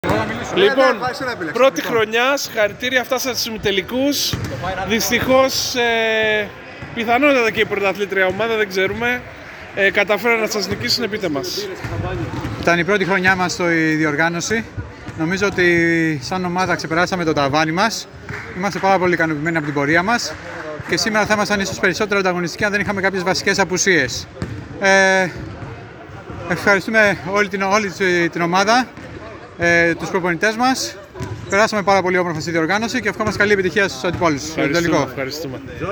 Δηλώσεις Πρωταγωνιστών: